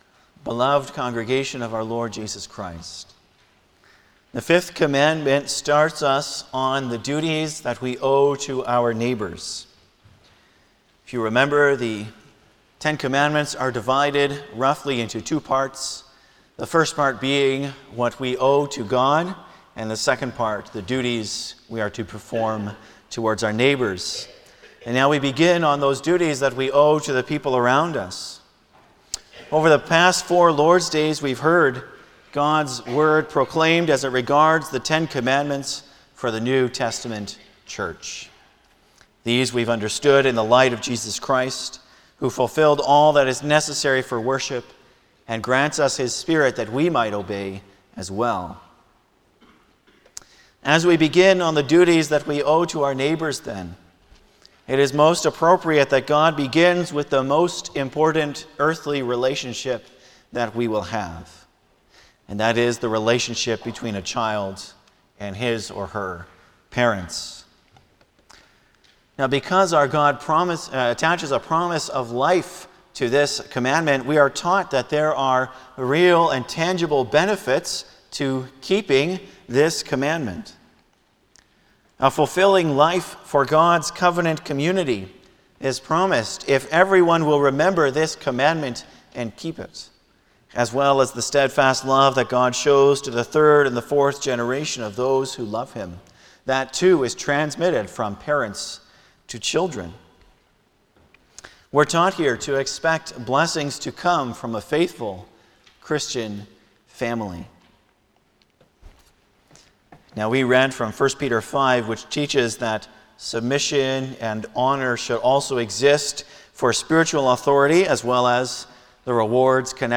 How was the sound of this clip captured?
Passage: Lord’s Day 39 Service Type: Sunday afternoon